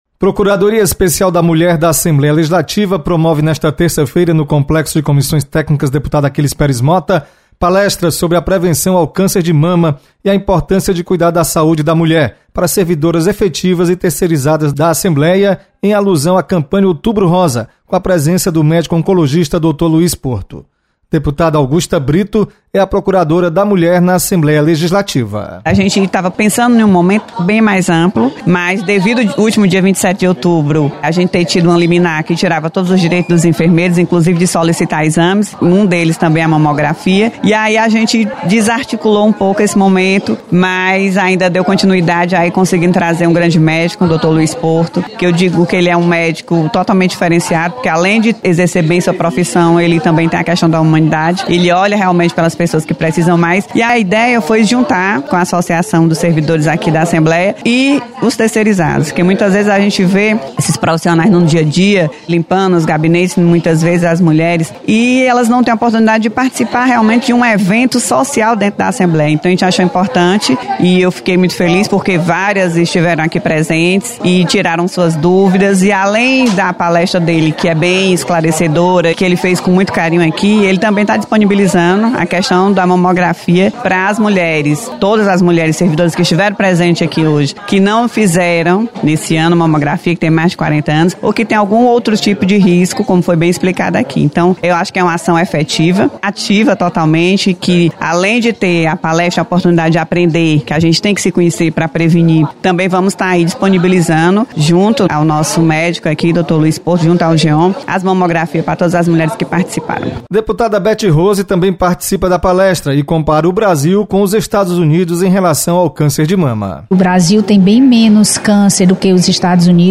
Palestra